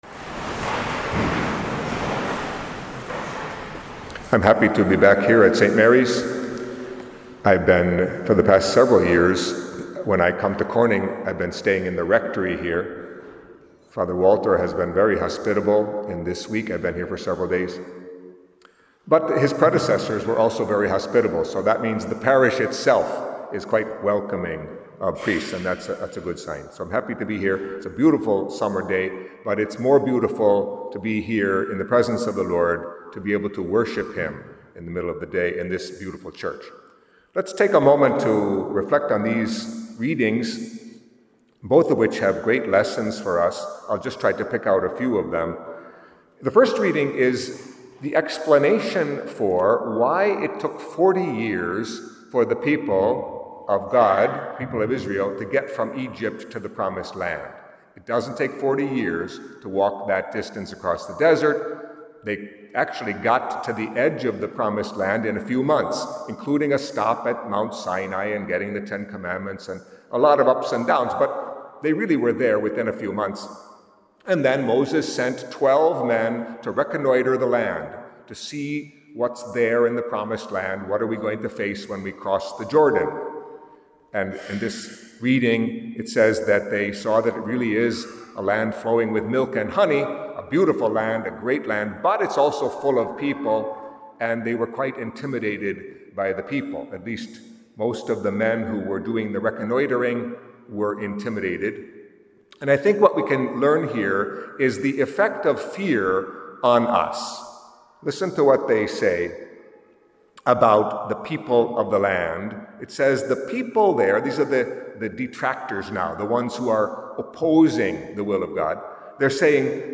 Catholic Mass homily for Wednesday of the Eighteenth Week in Ordinary Time